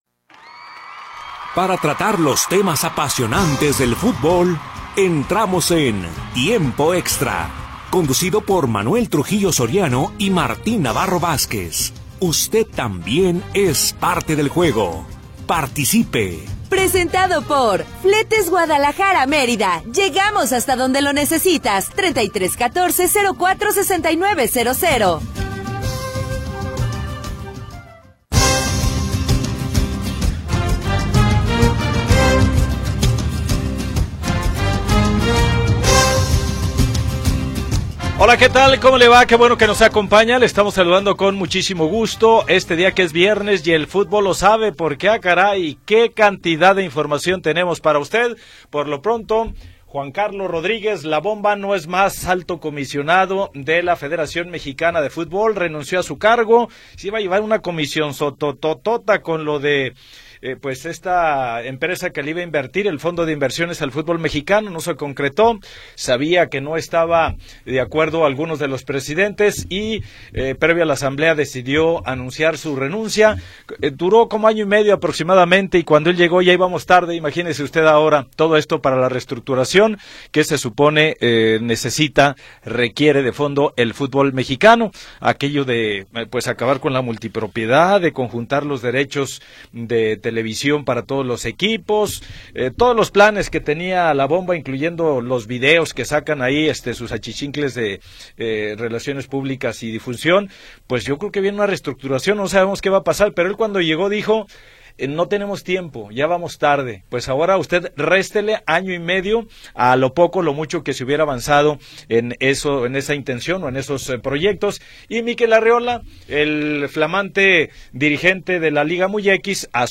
1 Metrópoli al Día 2da Hora - 10 de Marzo de 2025 44:18 Play Pause 8h ago 44:18 Play Pause Daha Sonra Çal Daha Sonra Çal Listeler Beğen Beğenildi 44:18 La historia de las últimas horas y la información del momento. Análisis, comentarios y entrevistas